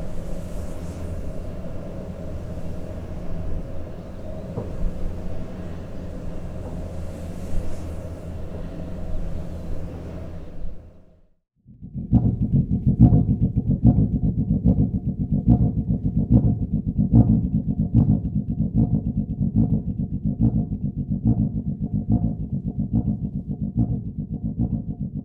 Jerez de la Frontera Soundscape
Soundscape Jerez.wav